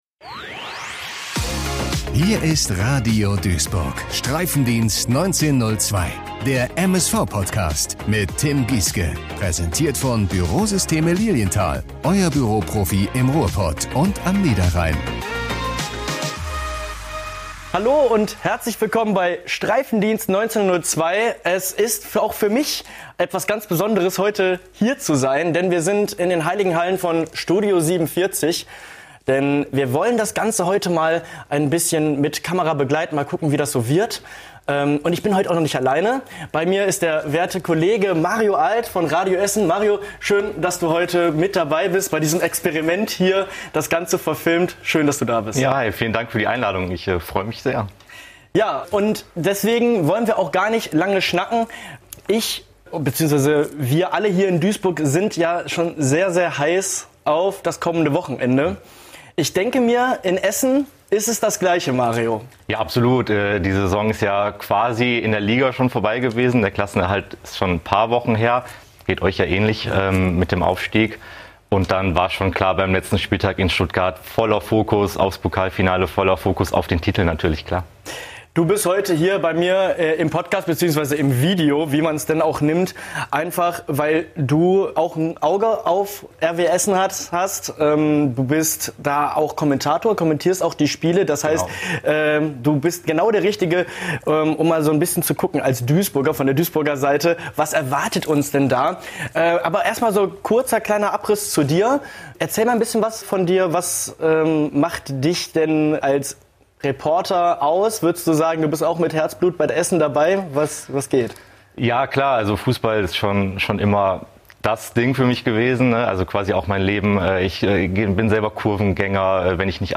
Der Streifendienst war für diese Folge bei Studio 47 zu Gast.